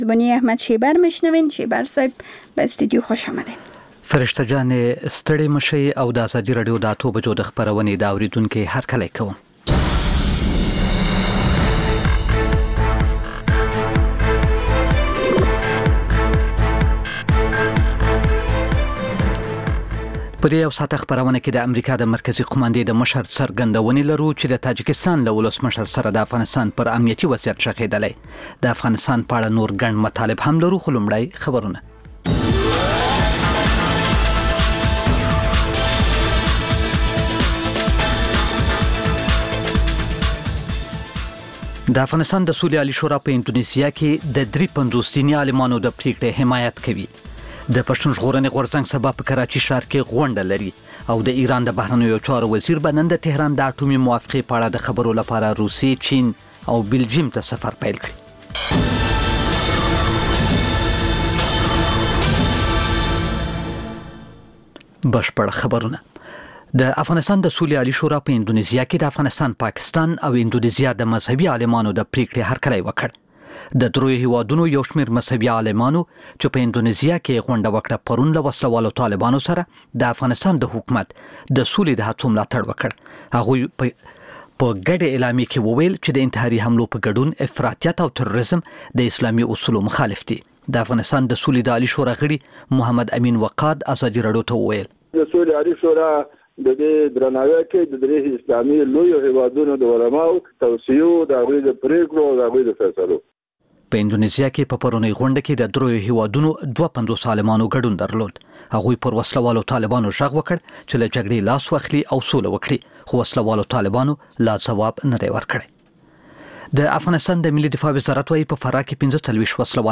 خبرونه او راپورونه، د ګوړې اچار